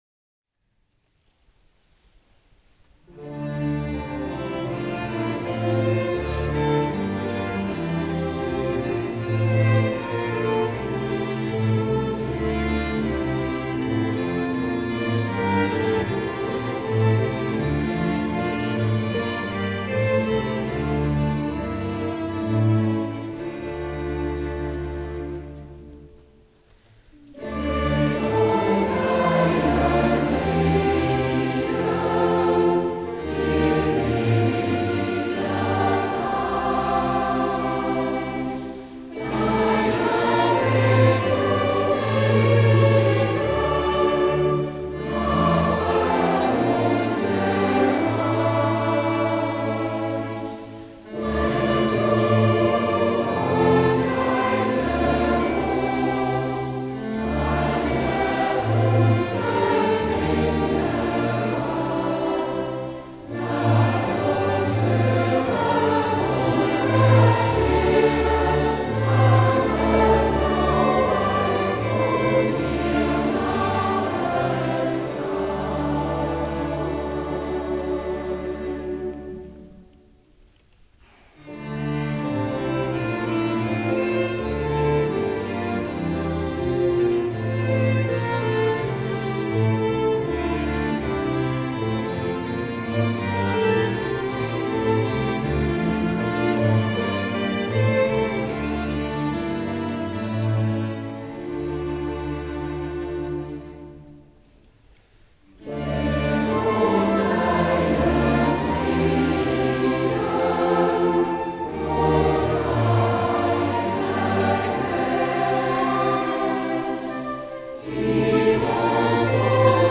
für Chor und Orchester
Gib uns deinen Frieden, Mühlenbach 24.10.2010